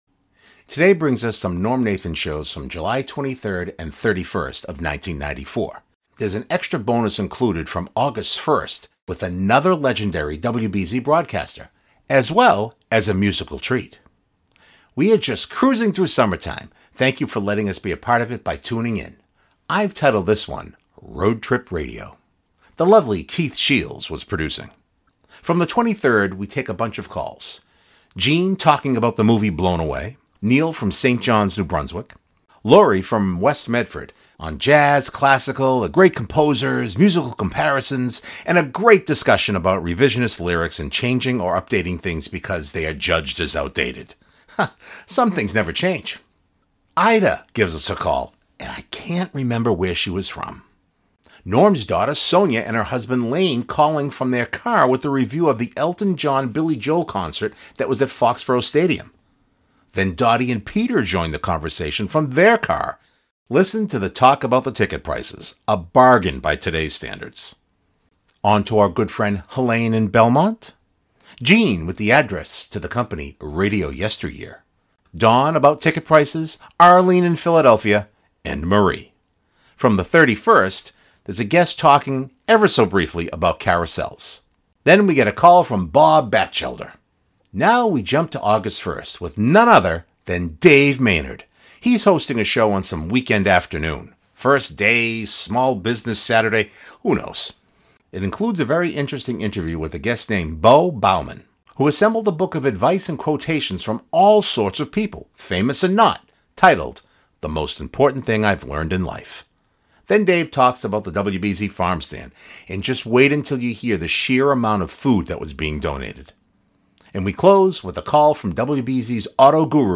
From the 31st: A guest talking, ever so briefly, about Carousels.